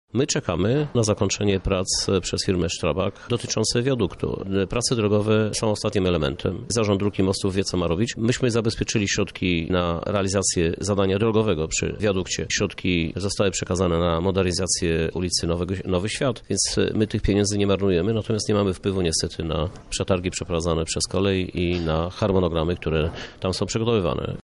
Więcej na ten temat mówi prezydent Lublina Krzysztof Żuk.